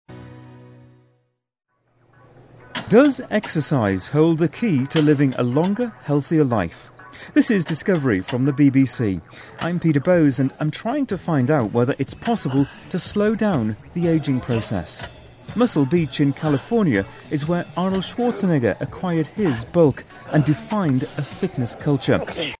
【英音模仿秀】不老奥秘 听力文件下载—在线英语听力室